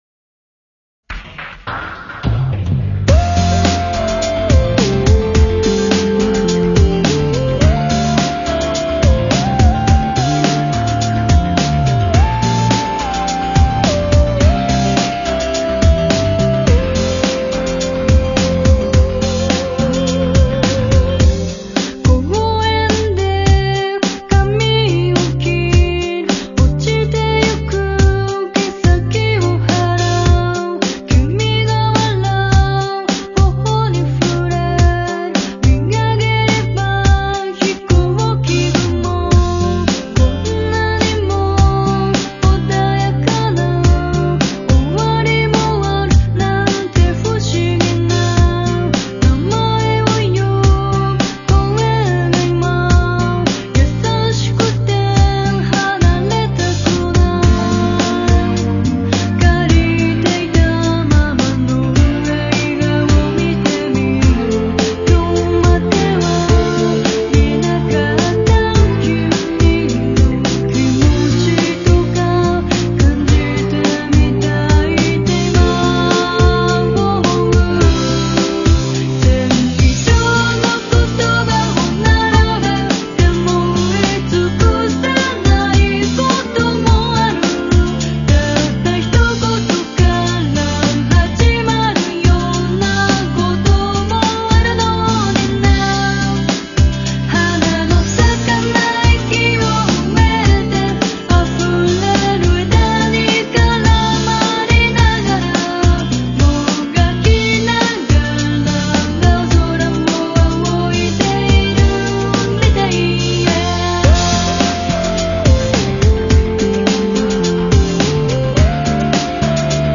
Un groupe de J-Pop (l'équivalent de nos variétés).